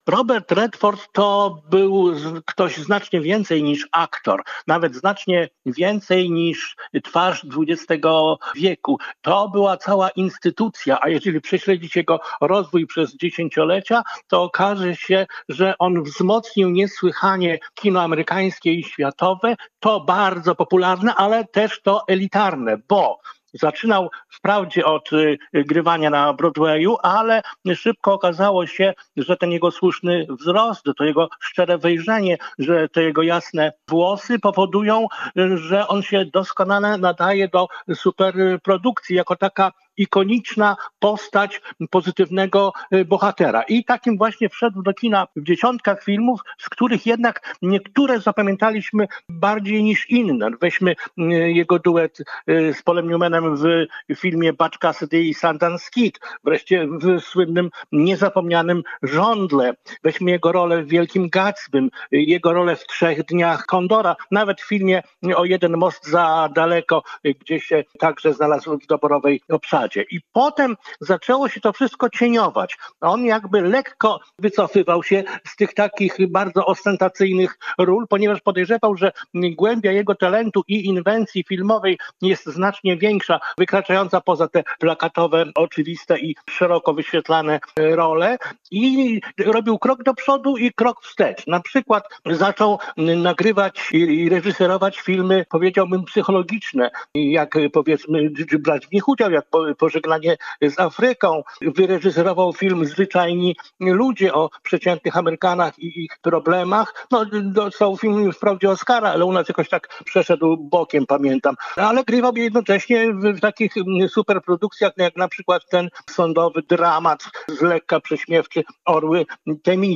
Wspomina go krytyk filmowy